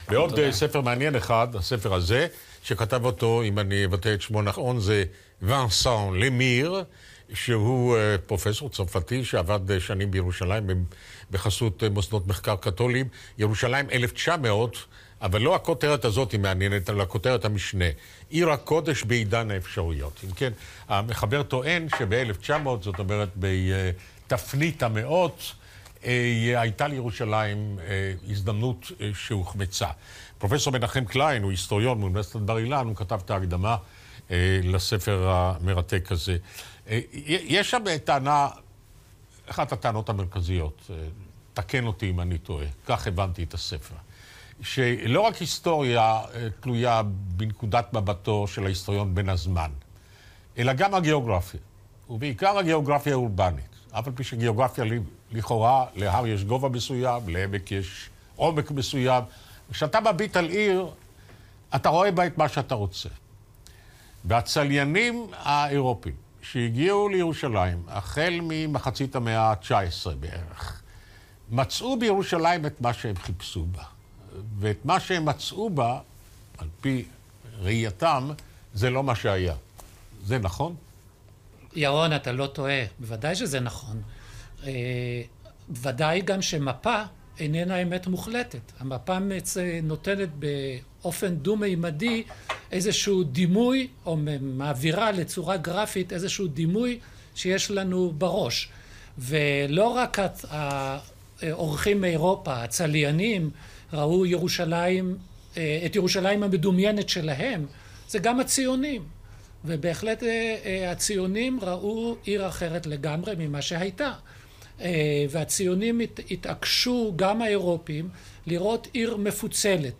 לונדון את קירשנבאום, ריאיון